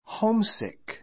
homesick hóumsik ホ ウ ム スィ ク 形容詞 うちを恋 こい しがる, 故郷を慕 した う, ホームシックの get [feel] homesick get [feel] homesick ホームシックにかかる I was very homesick during my stay in London.